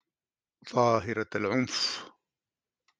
Sudanese Arabic